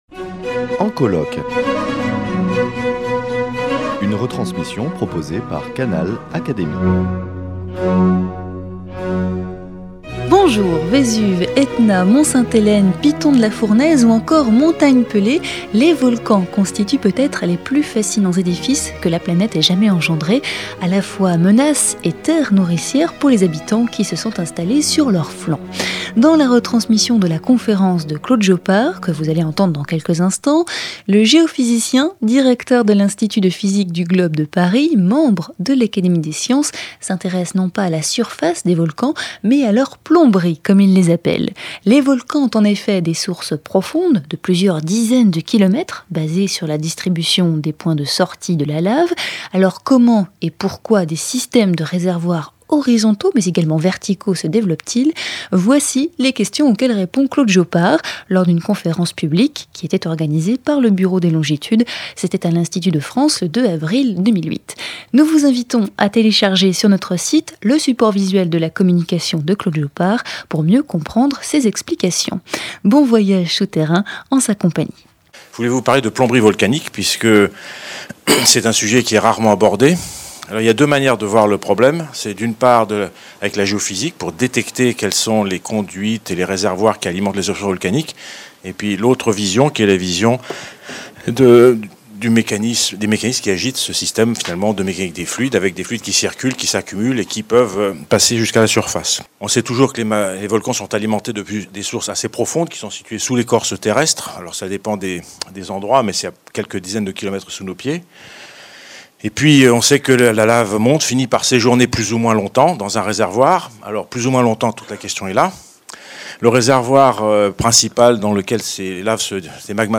dans cette conférence donnée au Bureau des longitudes